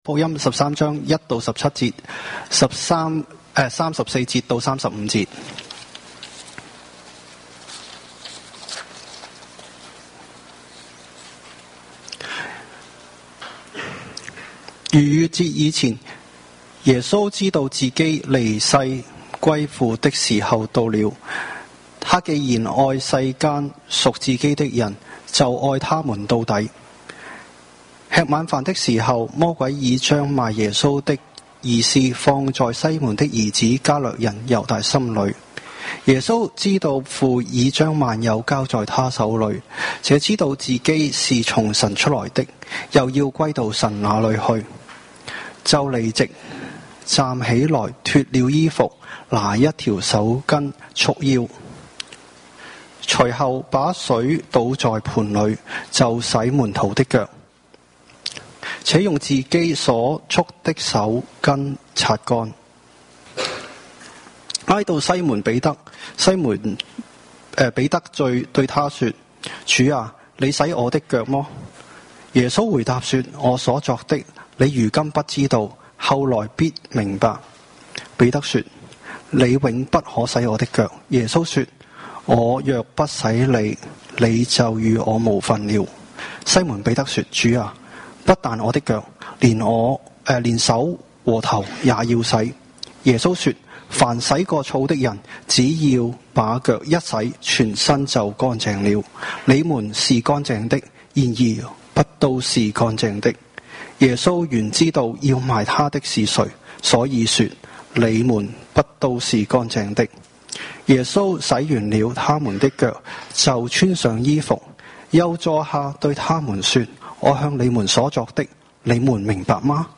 華埠粵語三堂